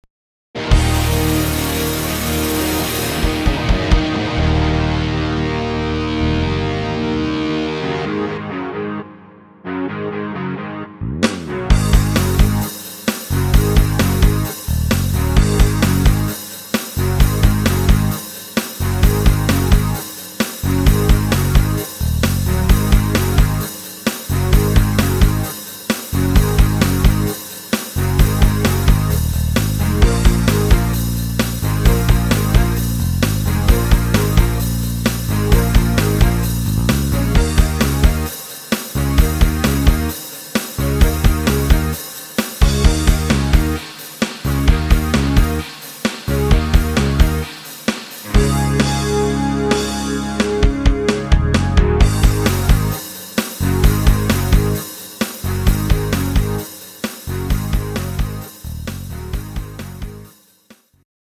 Rock-Klassiker neu arrangiert